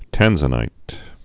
(tănzə-nīt)